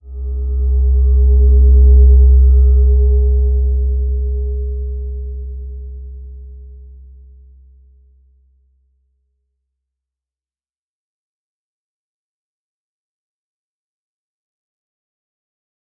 Slow-Distant-Chime-C2-f.wav